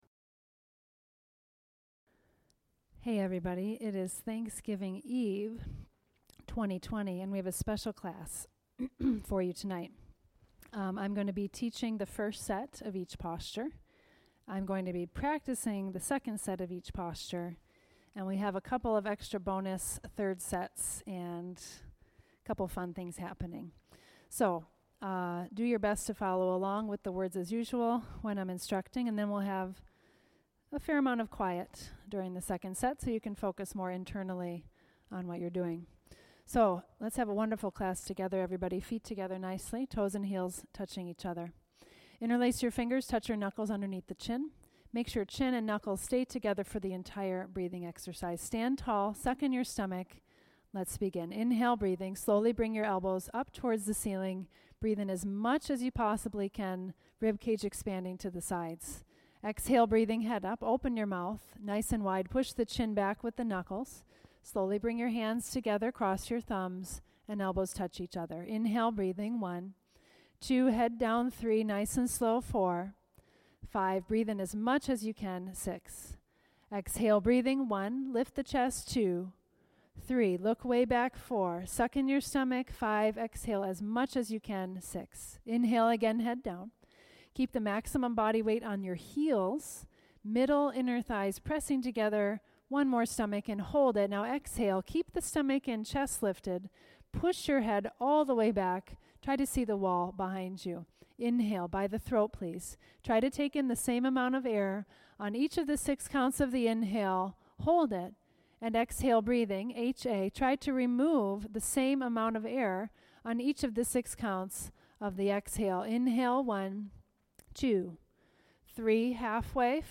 Thanksgiving Eve Special Class